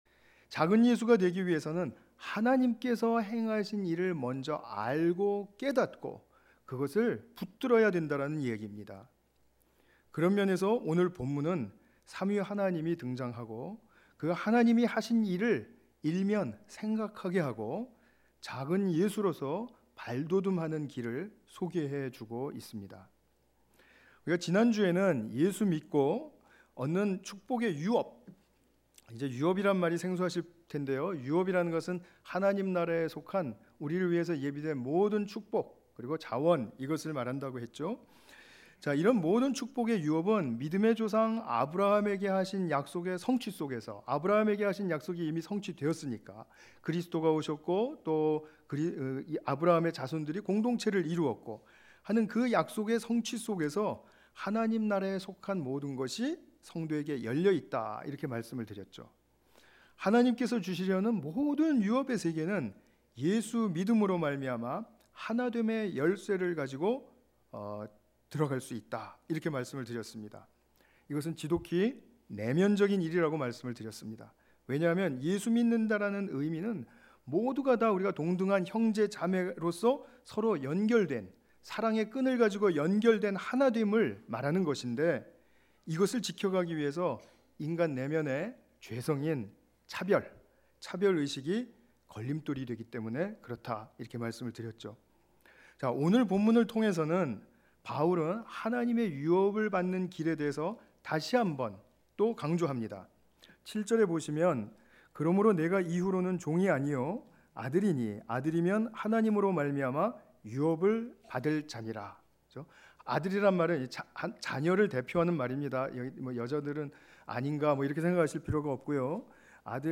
갈라디아서 4장 1 ~ 7절 관련 Tagged with 주일예배